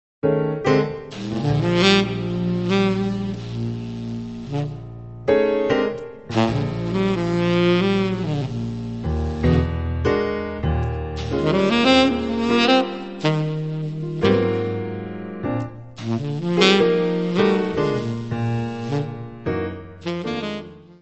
Descrição Física:  1 disco (CD) (53 min.) : stereo; 12 cm
Área:  Jazz / Blues